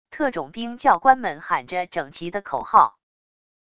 特种兵教官们喊着整齐的口号wav音频